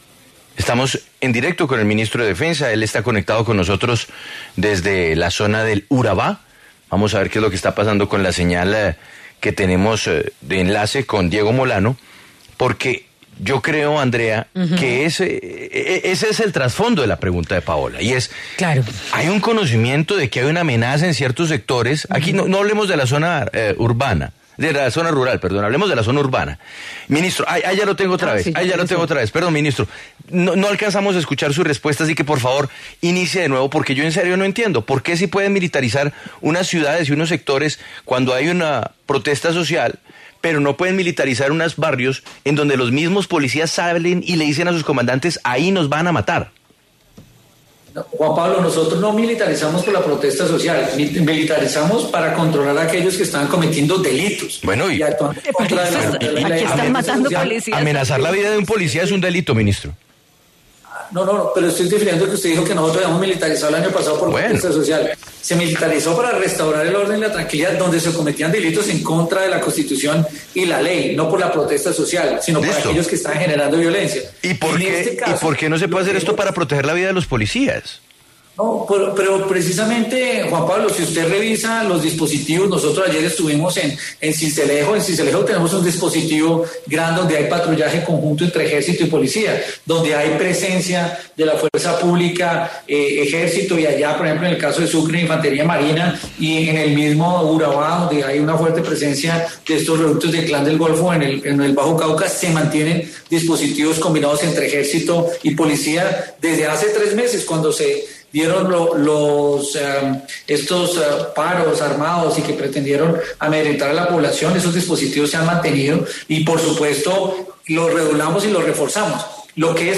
Diego Molano, el ministro de Defensa, se refirió en Sigue La W sobre los casos de policías asesinados en el país.
En el encabezado, sus declaraciones sobre los ataques contra policías.